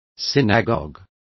Complete with pronunciation of the translation of synagogs.